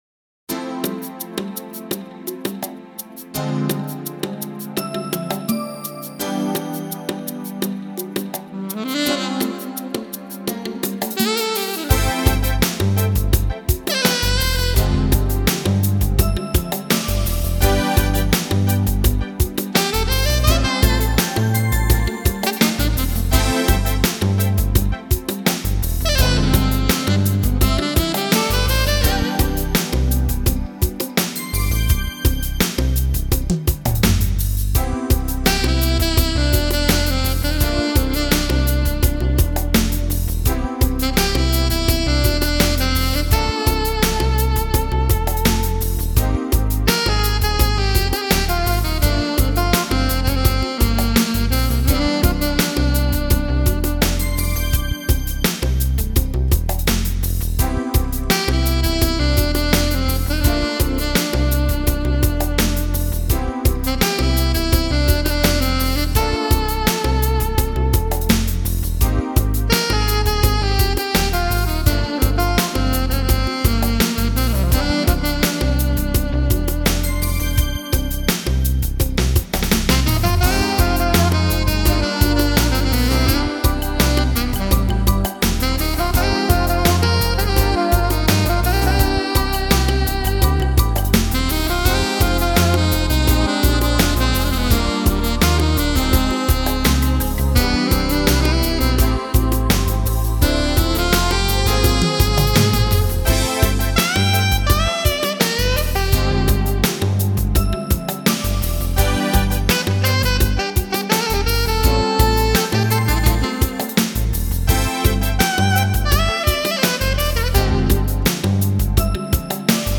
Mindi Abair 등과 더불어 대표적인 여성 스무스 재즈 색소포니스트라고 합니다.